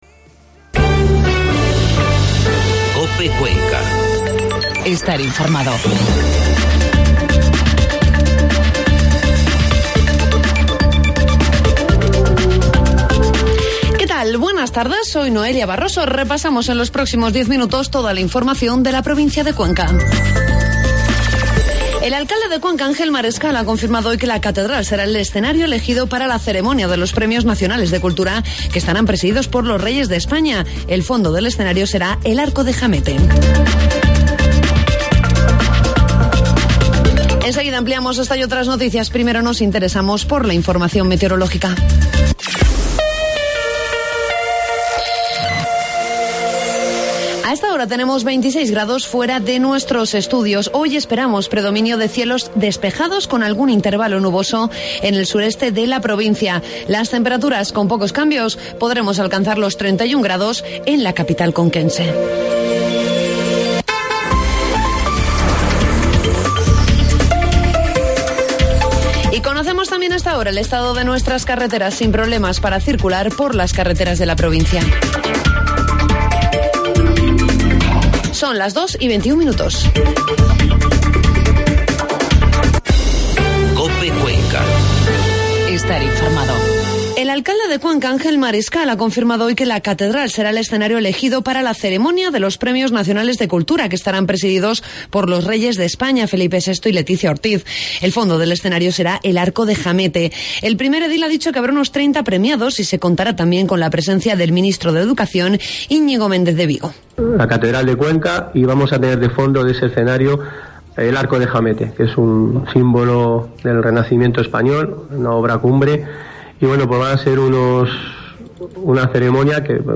Informativo mediodía COPE Cuenca 7 de septiembre